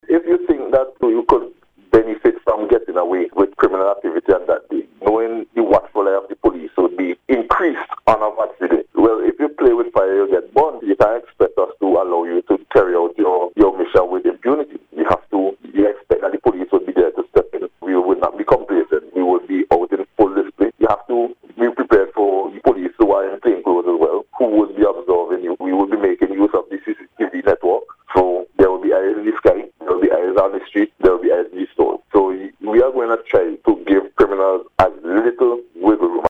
In an interview with NBC News